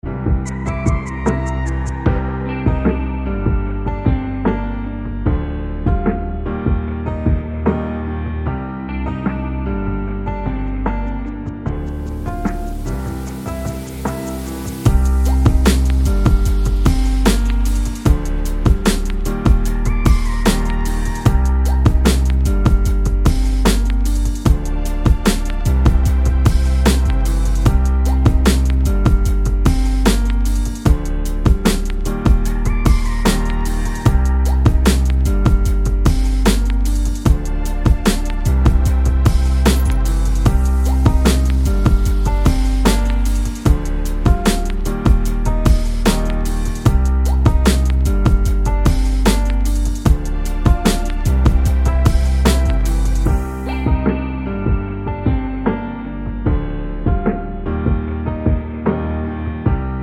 no Backing Vocals Finnish 4:09 Buy £1.50